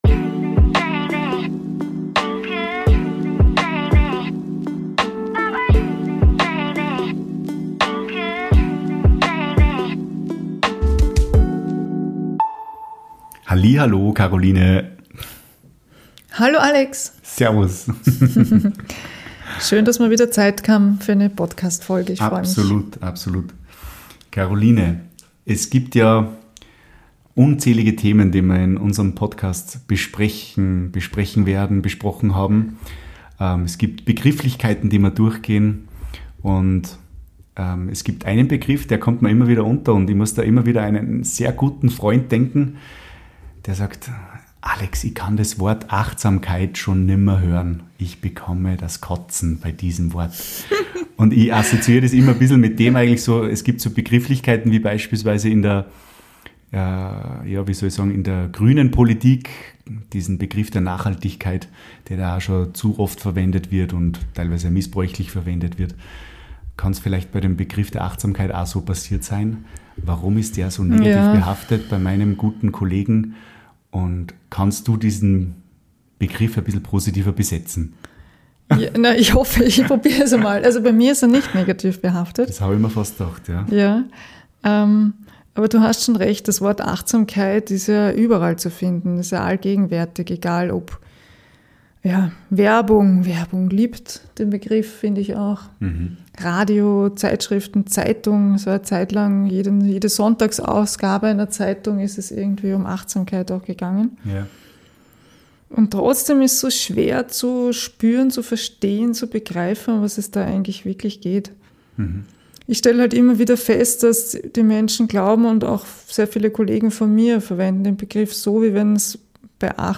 Freu dich auf eine ehrliche und spannende Diskussion über Fokus, Bewusstsein und warum Achtsamkeit mehr ist als ein Trend.